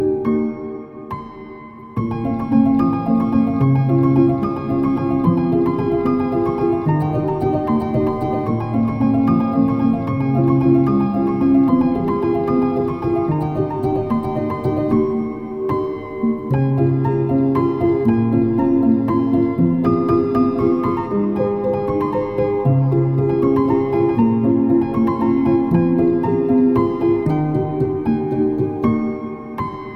# Easy Listening